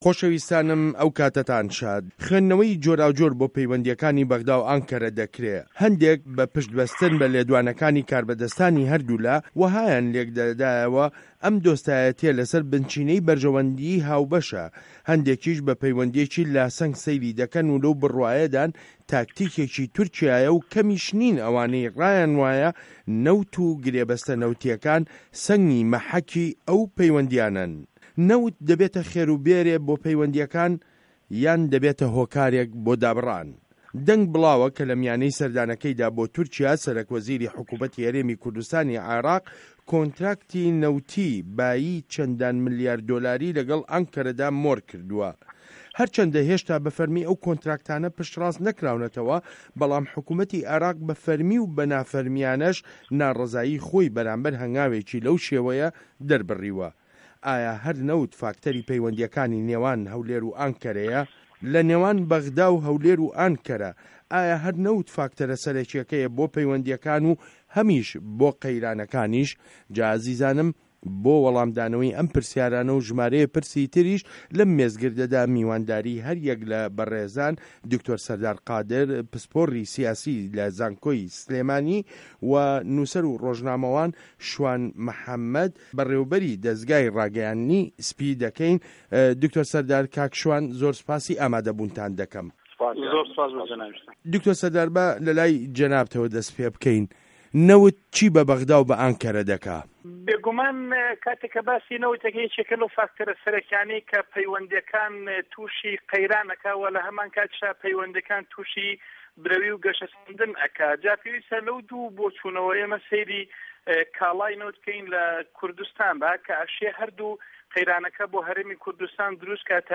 مێزگرد : نه‌وت له‌ نێوان به‌غدا و ئانکه‌ره